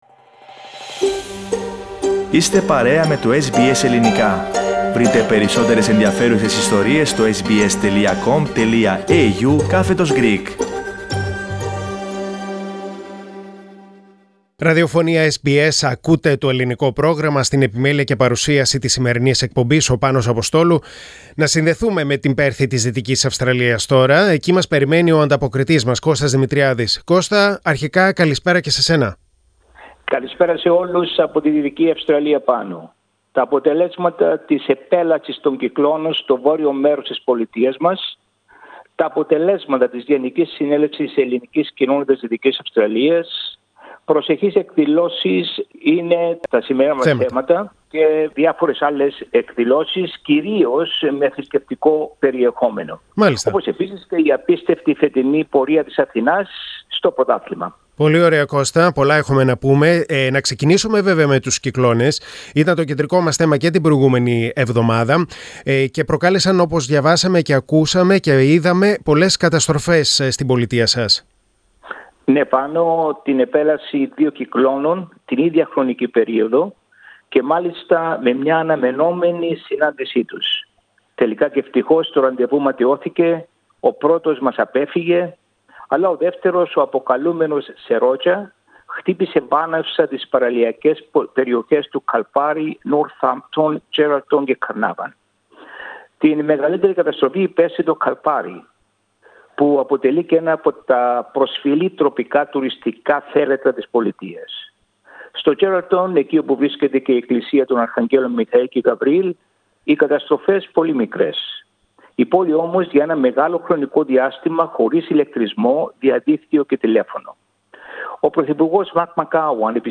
Ειδήσεις από την Δυτική Αυστραλία και νέα από την ομογένεια στην Ανταπόκριση από την Πέρθη.